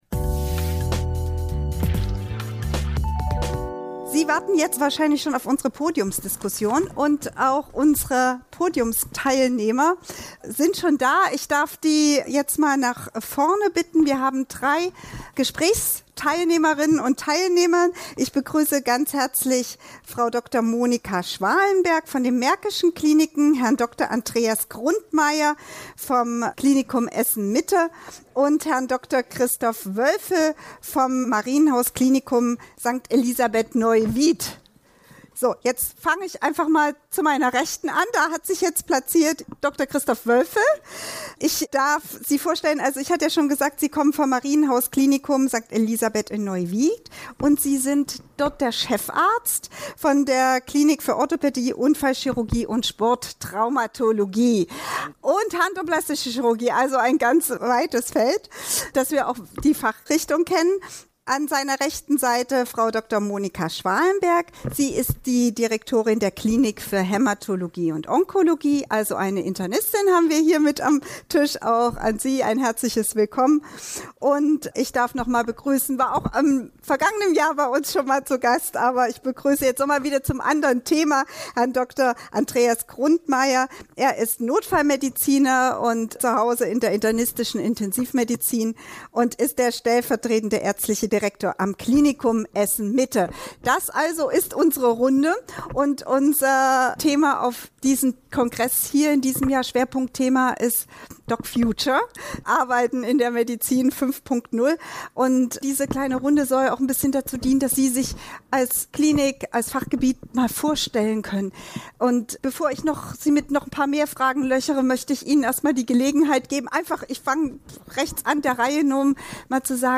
Bei der Podiumsdiskussion auf dem Operation Karriere-Kongress in Köln am 28. Oktober 2023 ging es um dieses Thema.